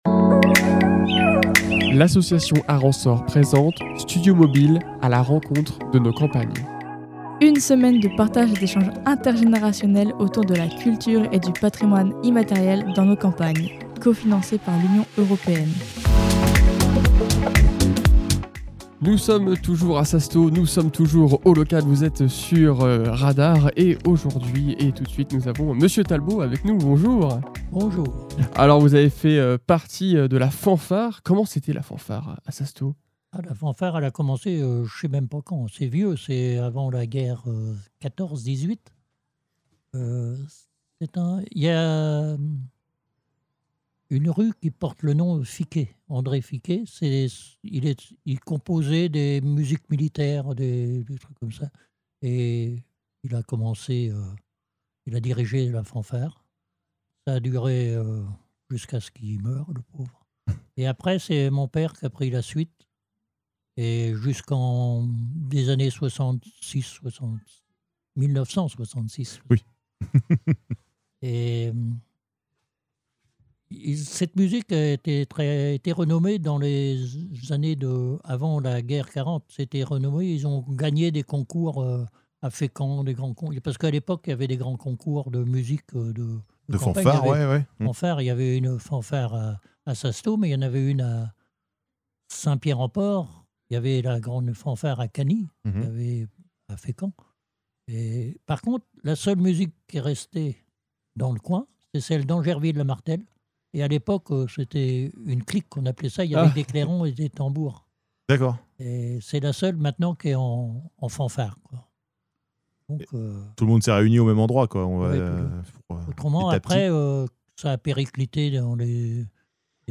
Studio mobile "A la rencontre de nos campagnes" caravane Studio mobile bonne humeur campagne joie sassetot-le-Mauconduit